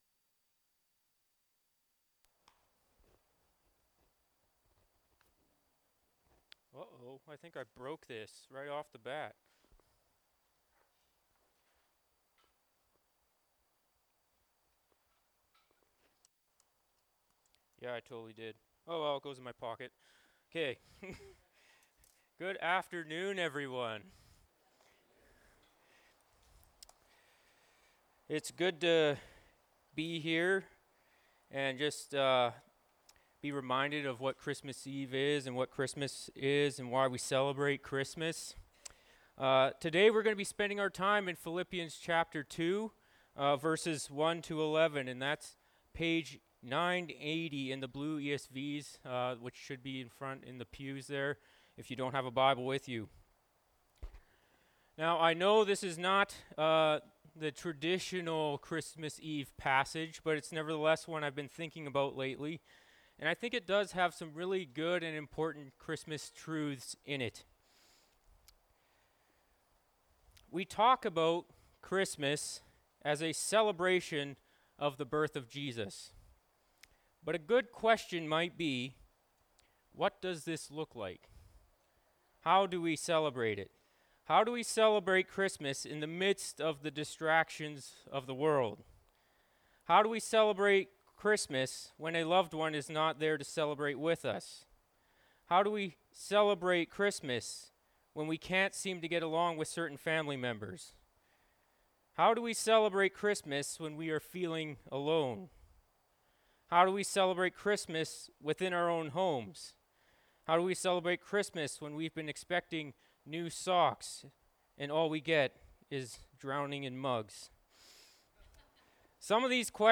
Dec 24, 2023 Jesus Christ is Lord (Philippians 2:1-11) MP3 SUBSCRIBE on iTunes(Podcast) Notes Discussion Sermons in this Series This sermon was preached in a joint Christmas Eve service in Salmon Arm.